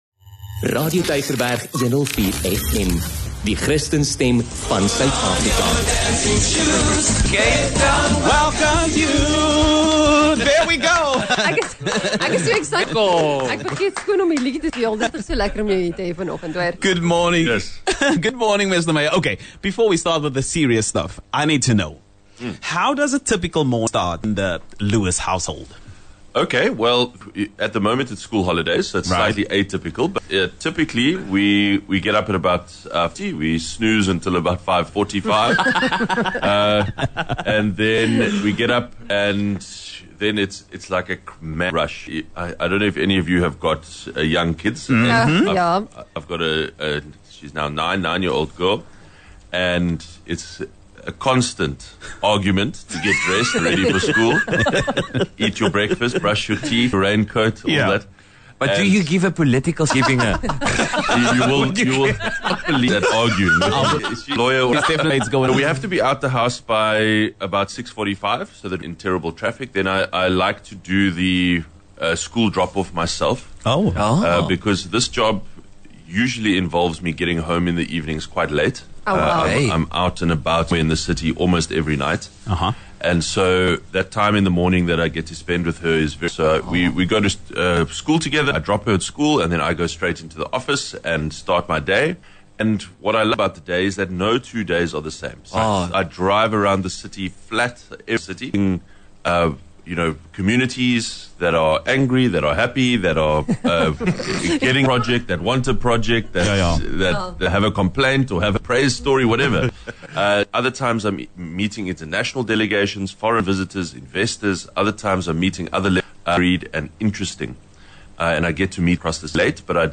16 Jul Die Real Brekfis gesels met Geordin Hill-Lewis, geliefde burgemeester van Kaapstad.